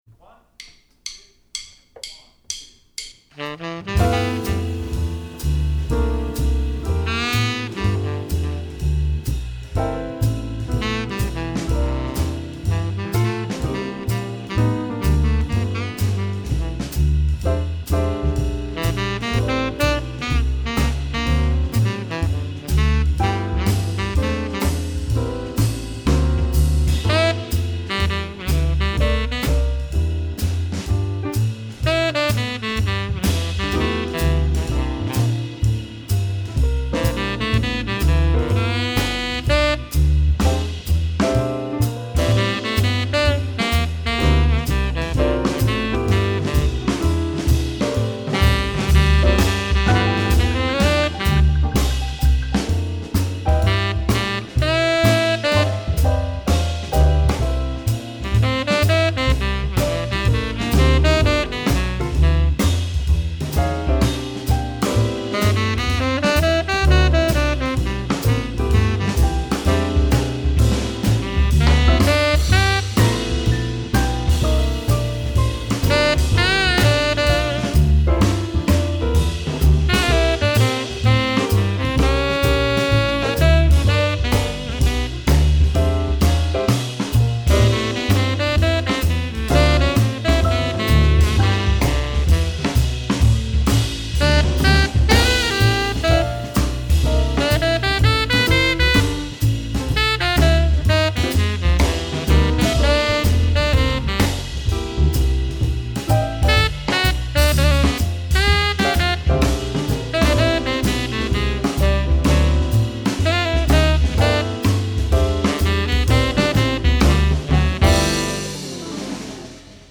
Saxophone Ténor